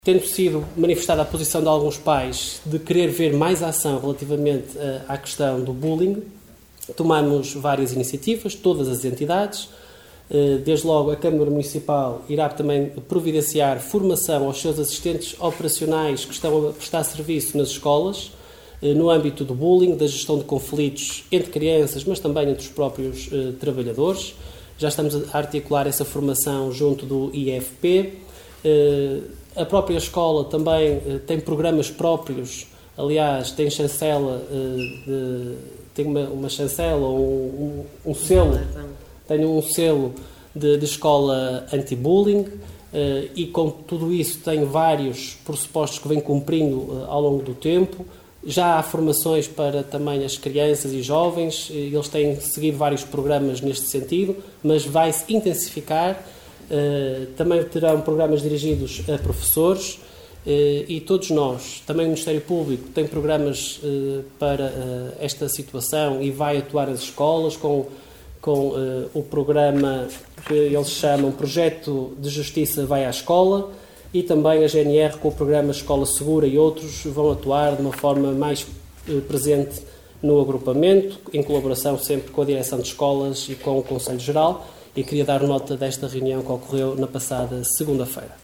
Esta quarta-feira, na última reunião do executivo, o presidente da Câmara fez um balanço da reunião, sublinhando que os casos que se têm verificado são pontuais e que as escolas do Agrupamento continuam a ser lugares seguros para os alunos.